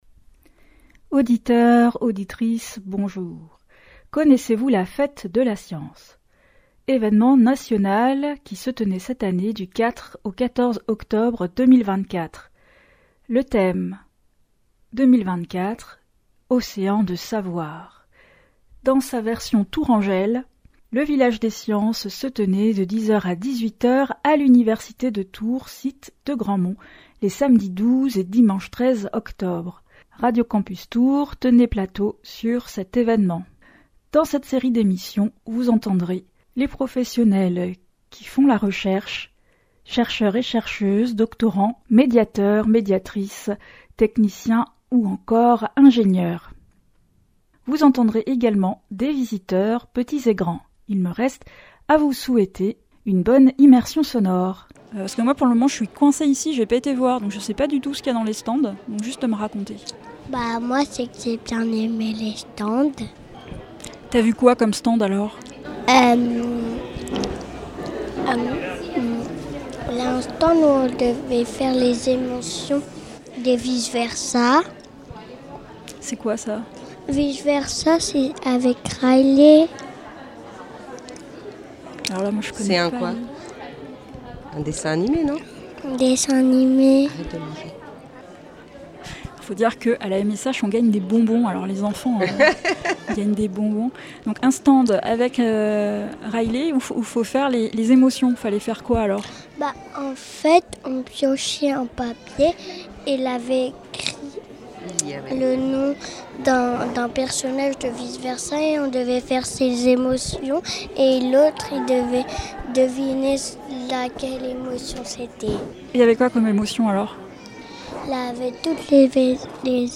Nous tenions plateau sur l’édition tourangelle qui se déployait cette année sur le site Grandmont de l’Université de Tours, à l’invitation de la MSH – Maison des Sciences de l’Homme Val de Loire.